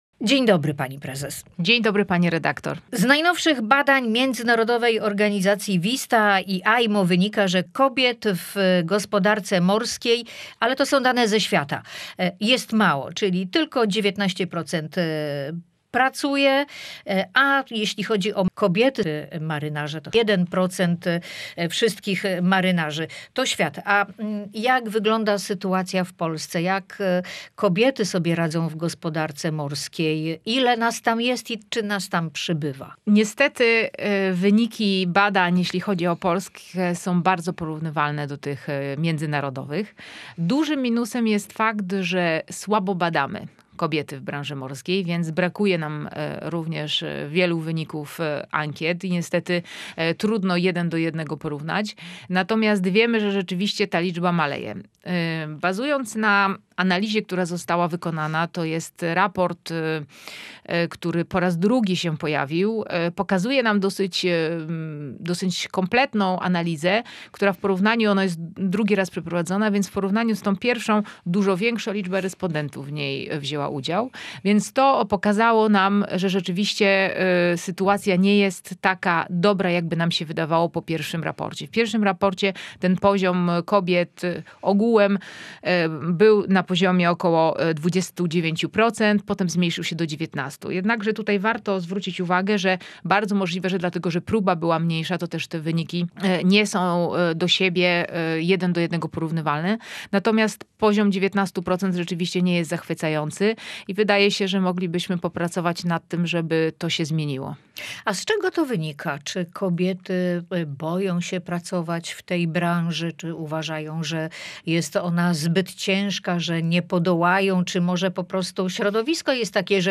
Co można zrobić, by ta sytuacja się zmieniła? Posłuchaj całej rozmowy
Gość Radia Gdańsk